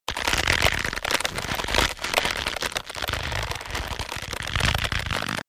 target_stretching_aim_loop.ogg